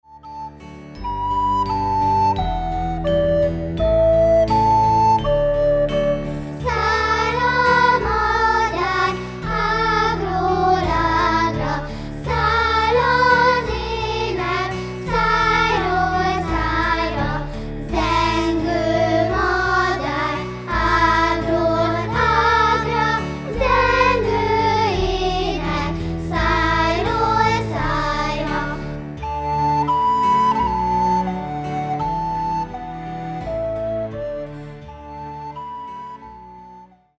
Az albumon a dalokat kisiskolások adják elő.
furulya
hegedű
tárogató